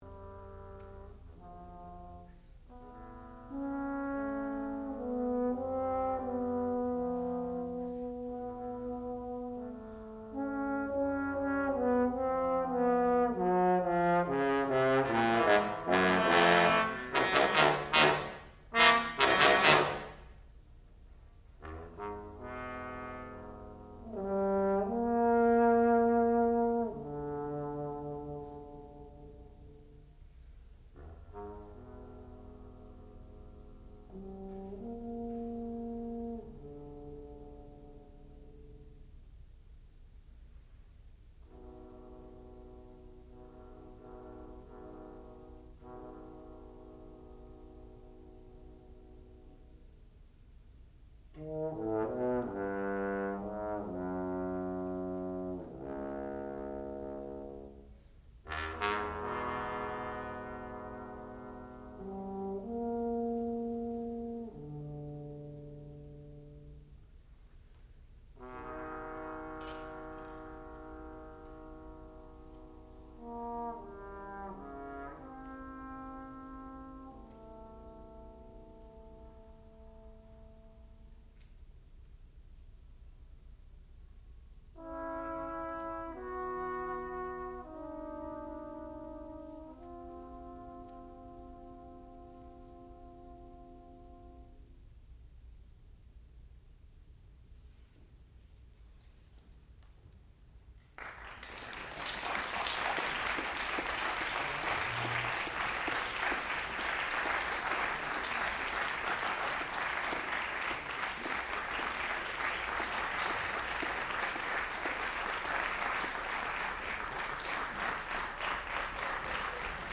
sound recording-musical
classical music
trombone
Master Recital
bass trombone